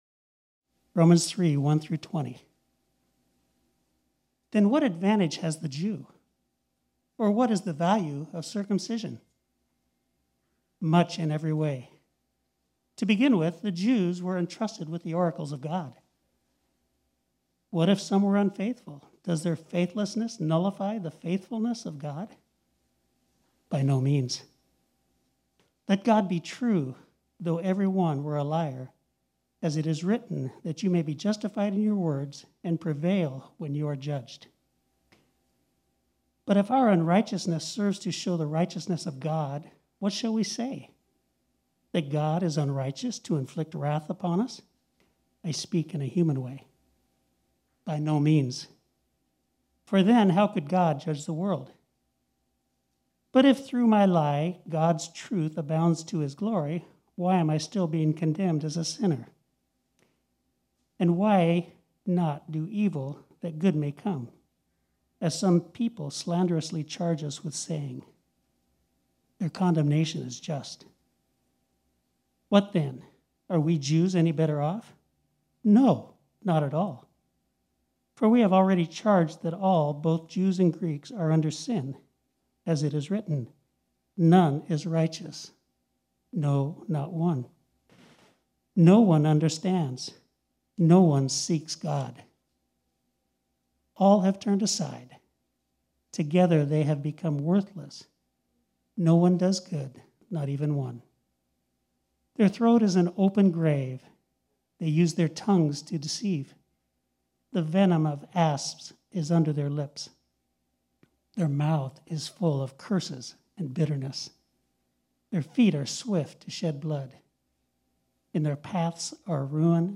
This sermon was originally preached on Sunday, February 21, 2021.